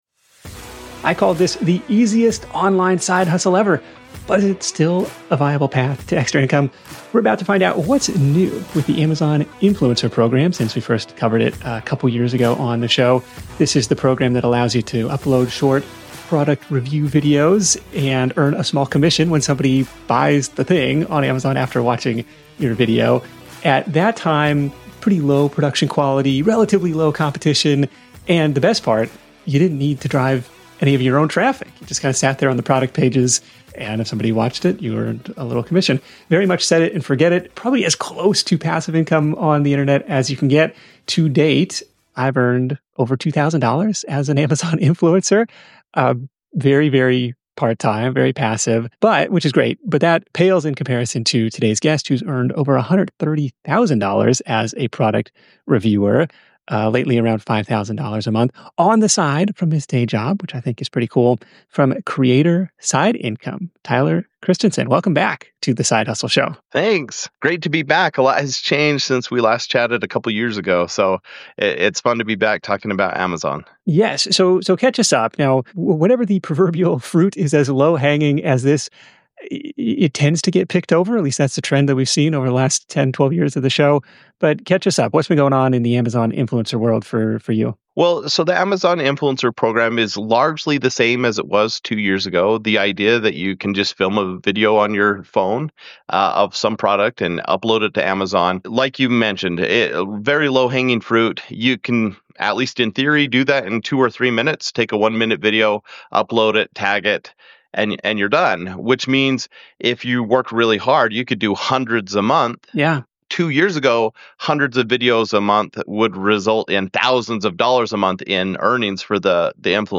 Tune in to Episode 656 the Side Hustle Show interview to hear